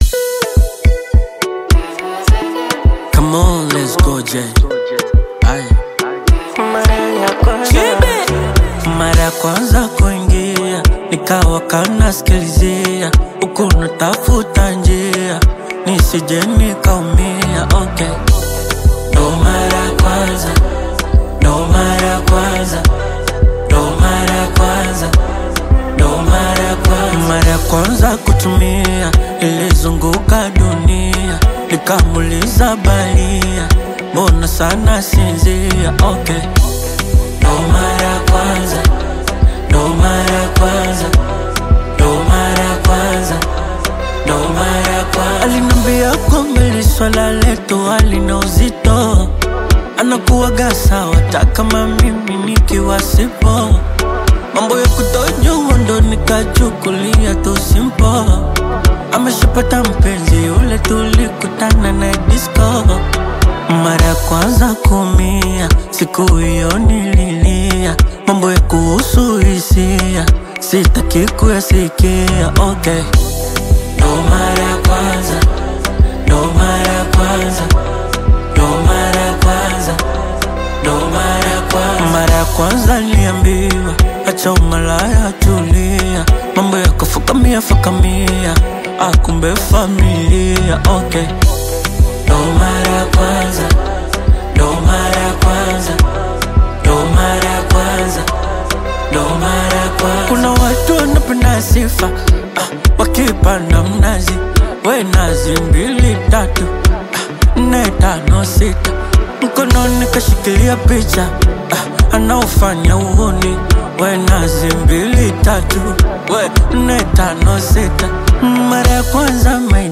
heartfelt Bongo Flava single
Genre: Bongo Flava